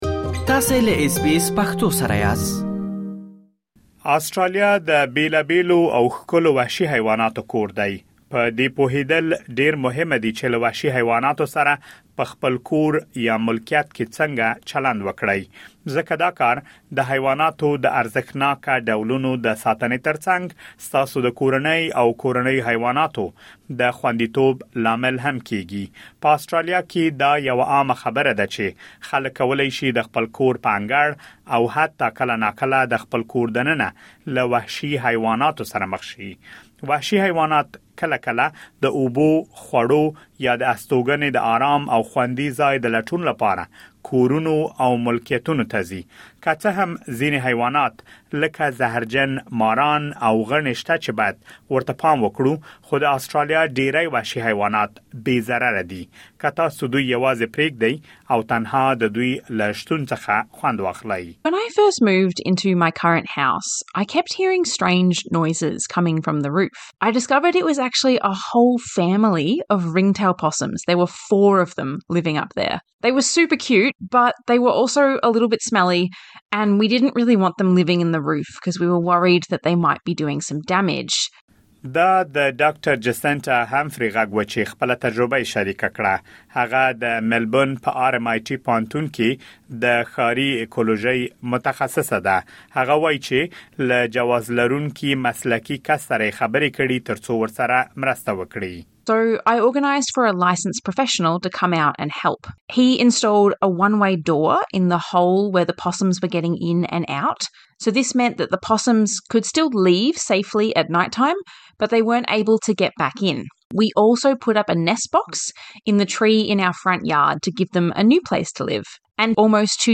په دې پوهېدل ډېر مهمه دي چې له وحشي حیواناتو سره په خپل کور یا ملکیت کې څنګه چلند وکړئ، ځکه دا کار د حیواناتو د ارزښتناکه ډولونو د ساتنې ترڅنګ، ستاسو د کورنۍ او کورنیو حیواناتو د خوندیتوب لامل هم کېږي. مهرباني وکړئ لا ډېر معلومات دلته په رپوټ کې واورئ.